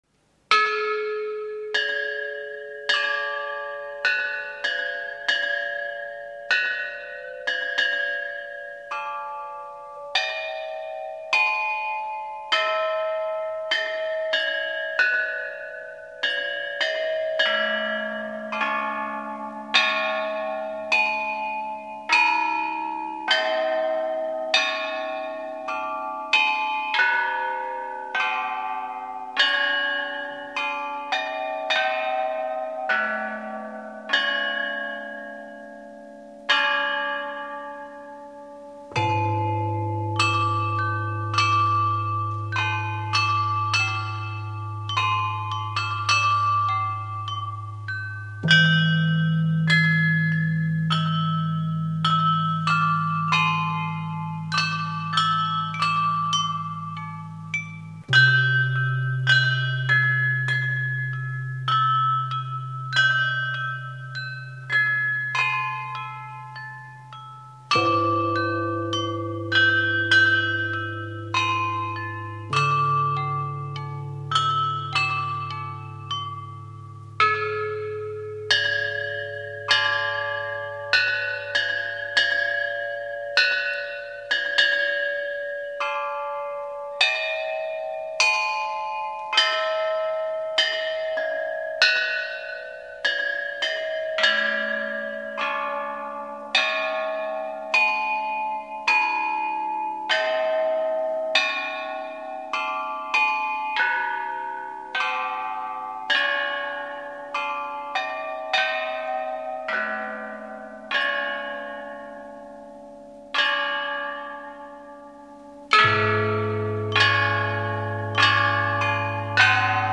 [12/4/2009]一曲编钟《枝竹词》带我们穿越时空的隧道，来在远古时代，聆听清脆、明亮、悠扬纯美的声音
[12/4/2009]一曲编钟《枝竹词》带我们穿越时空的隧道，来在远古时代，聆听清脆、明亮、悠扬纯美的声音 编钟 编钟是我国古代的一种打击乐器，用青铜铸成，它由大小不同的扁圆钟按照音调高低的次序排列起来，悬挂在一个巨大的钟架上，用丁字形的木锤和长形的棒分别敲打铜钟，能发出不同的乐音，因为每个钟的音调不同，按音谱敲打，可以演奏出美妙的乐曲。